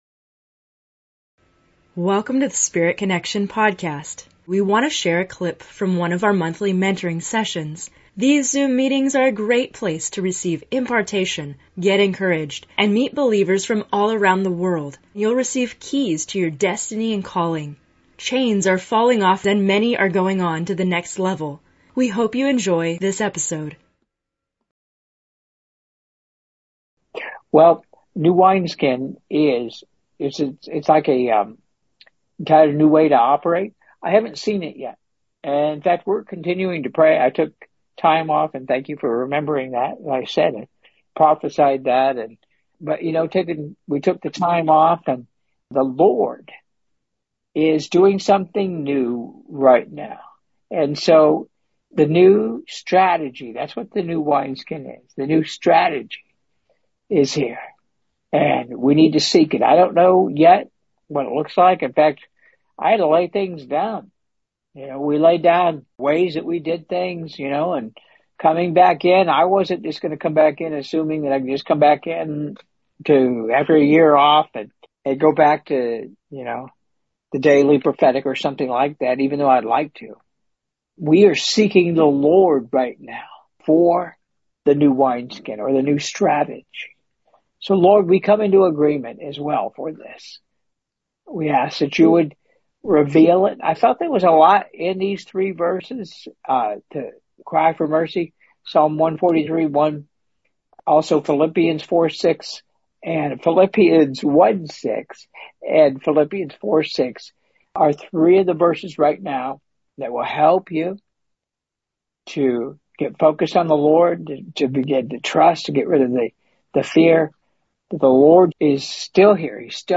In this episode of Spirit Connection, we have a special excerpt from a Q&A session in a recent Monthly Mentoring Session.